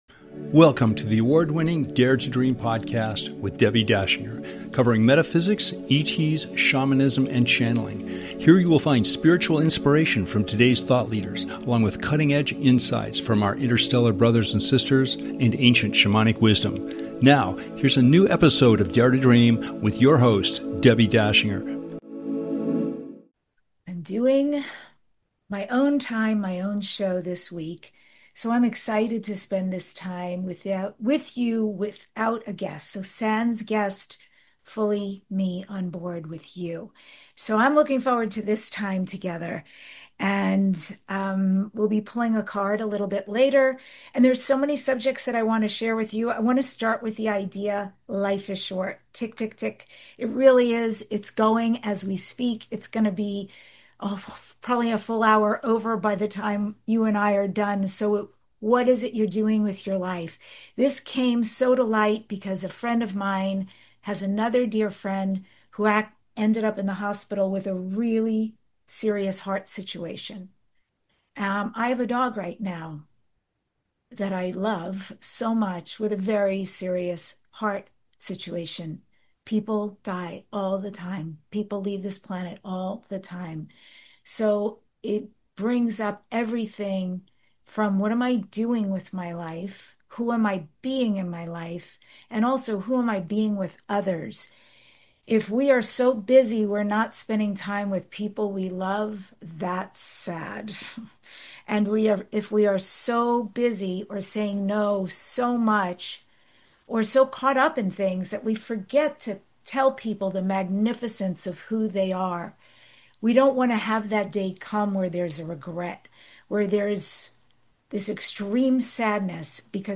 The award-winning DARE TO DREAM Podcast is your #1 transformation conversation.